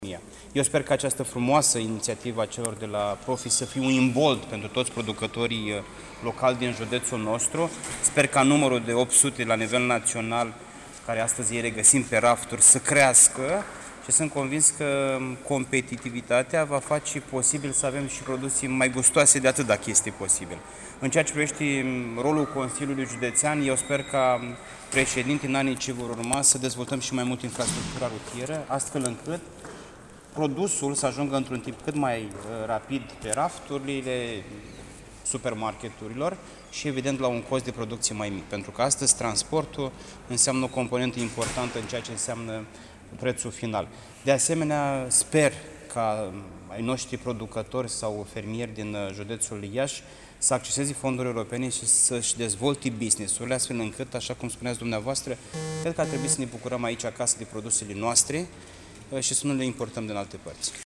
Președintele Consiliului Județean Iași, Costel Alexe: ” Vrem să dezvoltăm infrastructura astfel încît să ajungă mai repede produsele la raft „